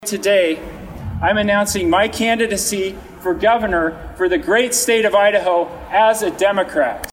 Sandpoint mayor and Lewiston native Shelby Rognstad announced Monday that he will run in the Idaho governor’s race as a Democrat. Rognstad, who was re-elected as mayor of Sandpoint in 2019, made the announcement to supporters at the Lewis Clark Hotel in Lewiston.